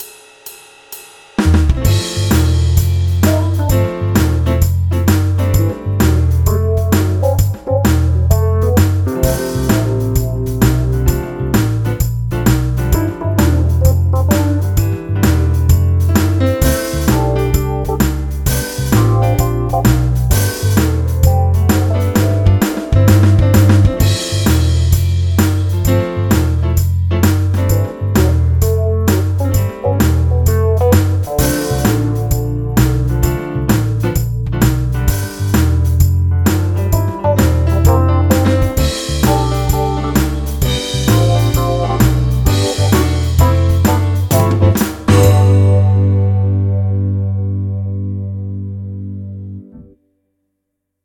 ベース、生ドラム、パーカッションと、フリーでは手に入りにくい音源に偏ってますが、痒いところをよく知ってますね。
ピアノとエレピ以外は
曲のほうですが、ブルースの上に変なコード
でも冒頭のFmaj7/Gは気に入ったのでいつか使おうと思います。